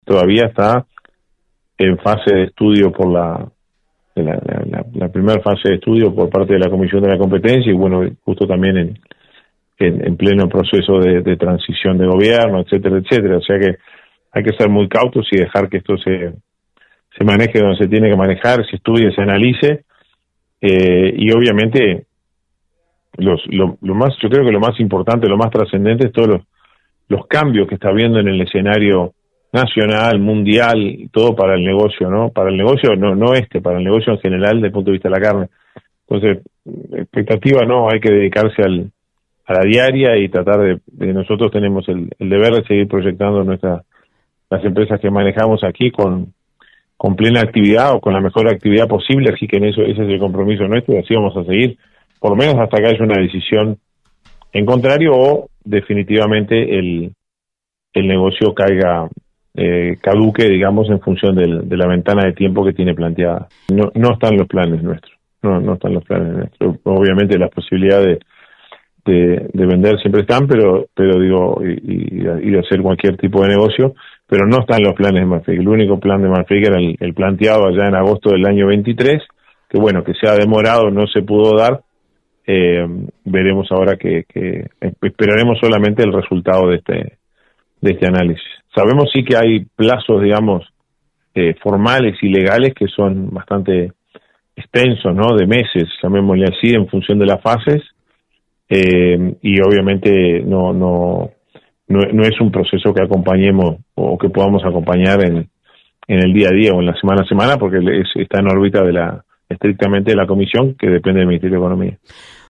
En diálogo con Radio Carve